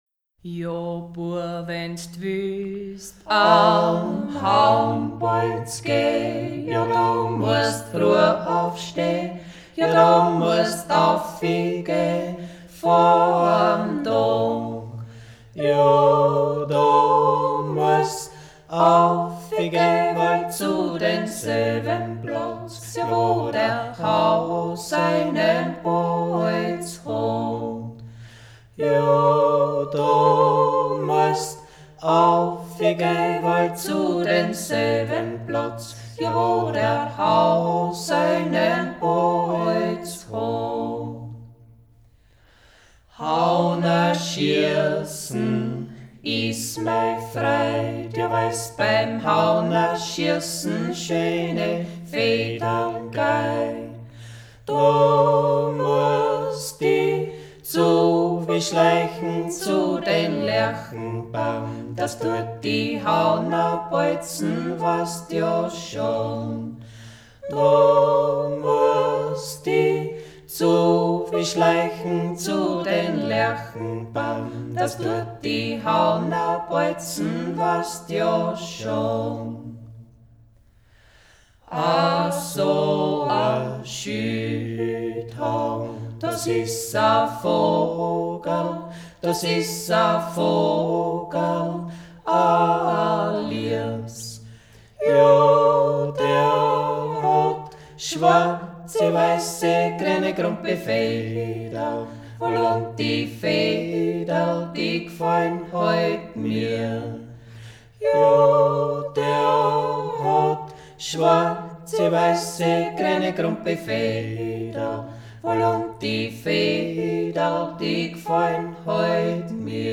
Jodler und Jodler-Lied – Ungeradtaktig
Jodler, Jodler-Lied – ungeradtaktig; niederösterreich-steirisches Wechselgebiet; Sozialstruktur; regionaler Dialekt
Yodel, Yodel-Song – triple metre (3/4): Lower Austrian and Styrian Wechsel-region; social structure; local dialect
Folk & traditional music